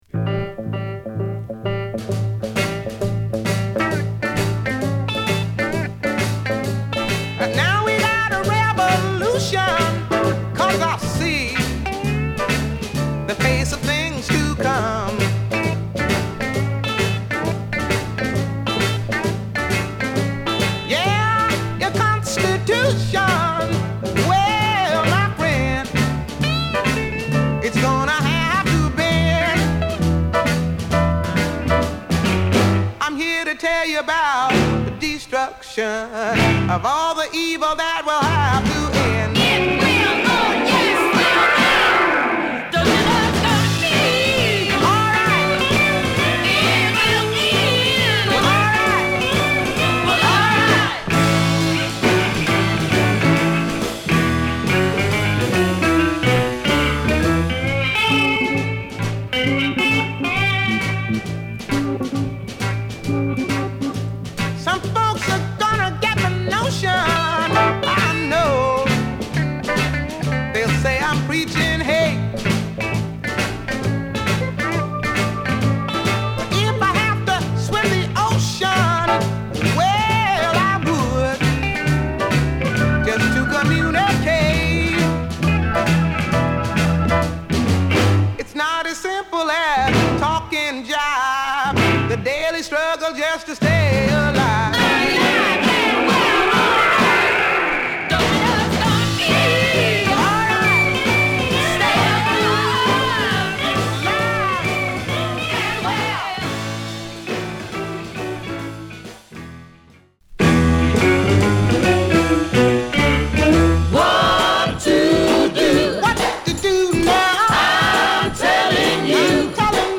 ファンキーなリズムにハスキーなヴォーカルが乗るブルースチューン